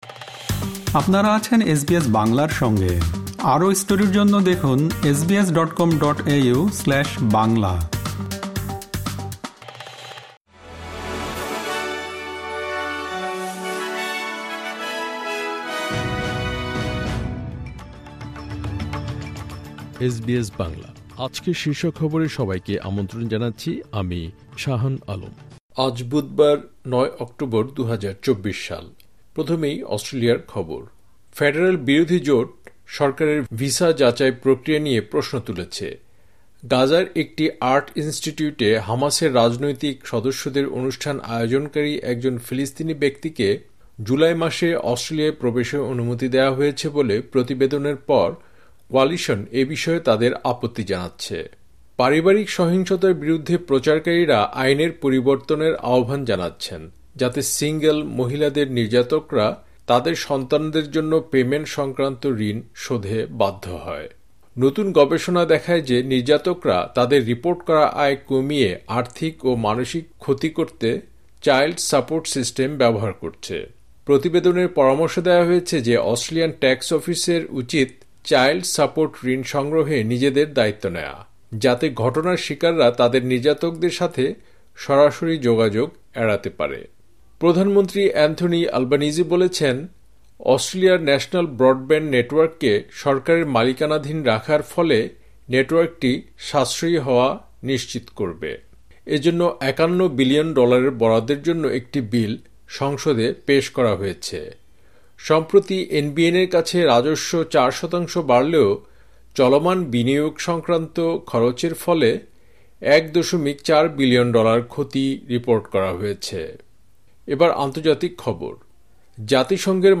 এসবিএস বাংলা শীর্ষ খবর: ৯ অক্টোবর, ২০২৪